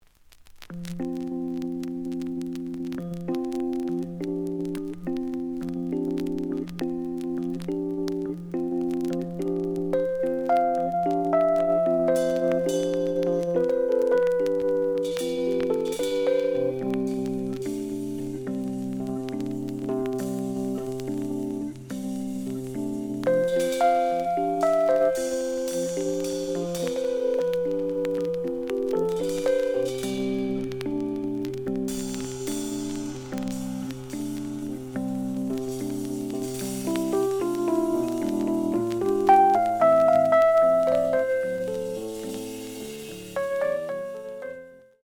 The audio sample is recorded from the actual item.
●Genre: Jazz Rock / Fusion